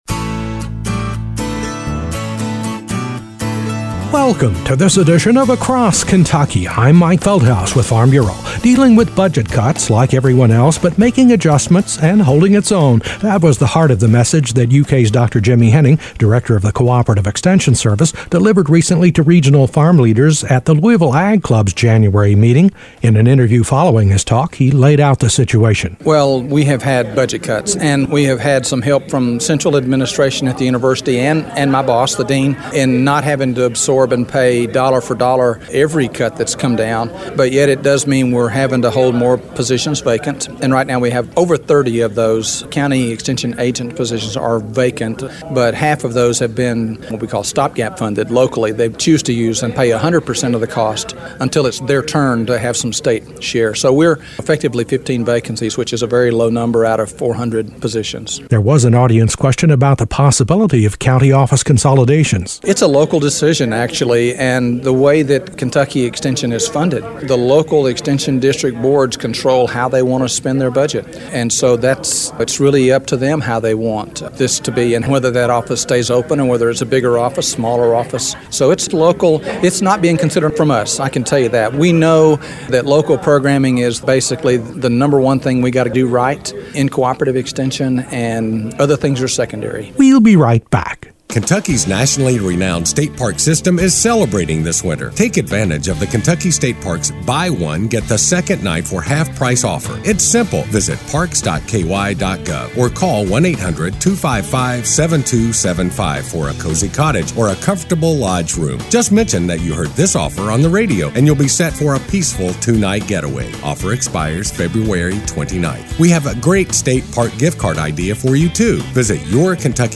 A special report